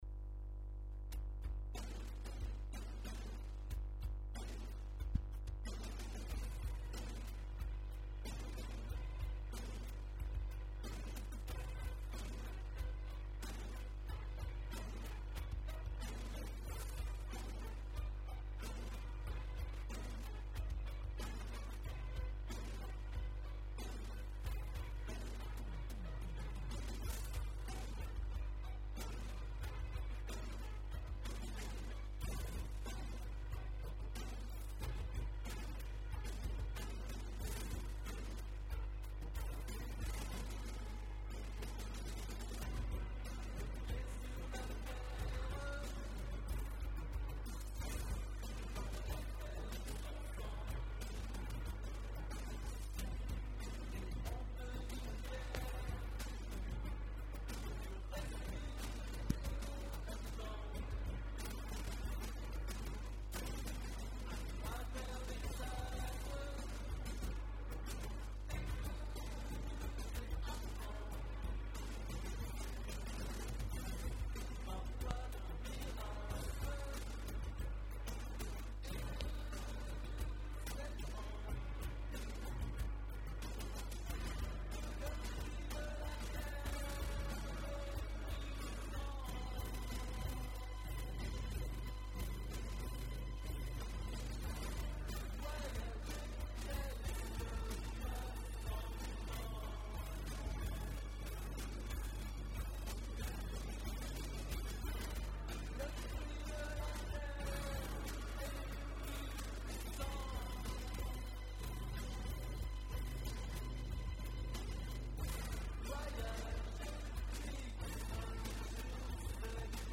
ceci est une pré-maquette réalisée @ home sur un Yamaha MT8X à K7